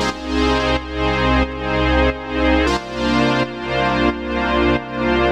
Index of /musicradar/sidechained-samples/90bpm
GnS_Pad-MiscA1:4_90-C.wav